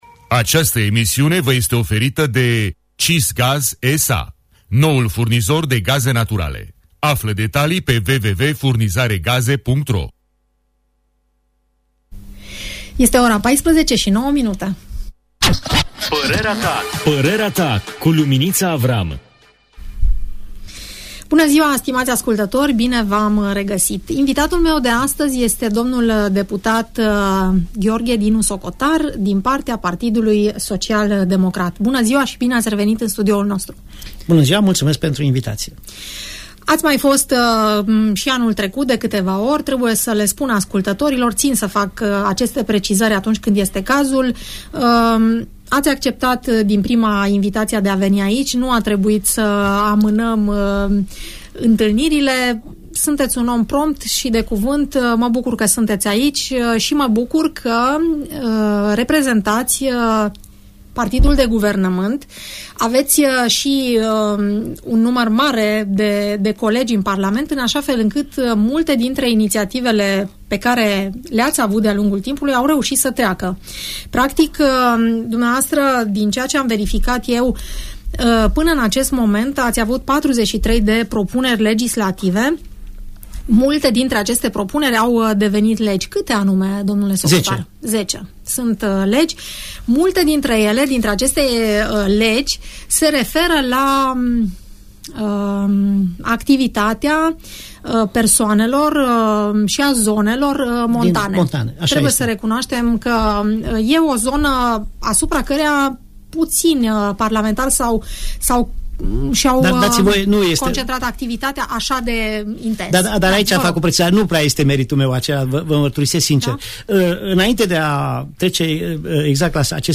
Audienţă radio cu deputatul Dinu Socotar - Radio Romania Targu Mures